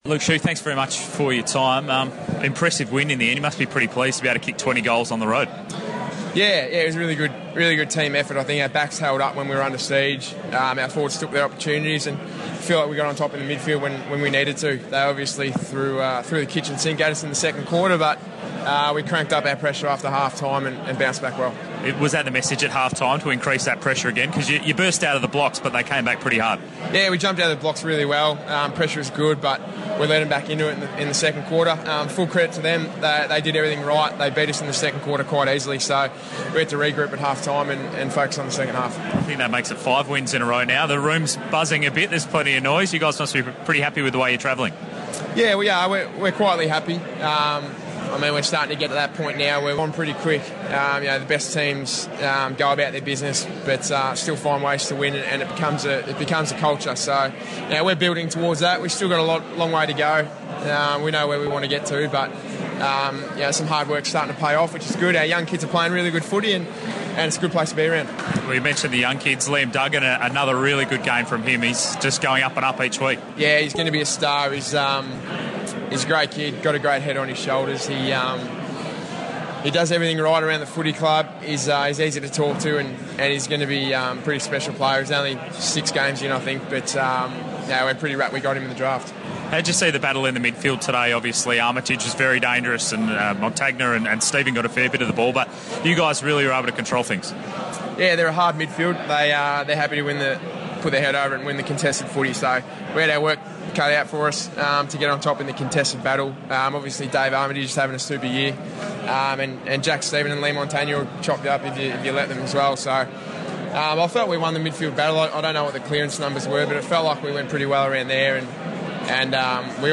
Luke Shuey Post game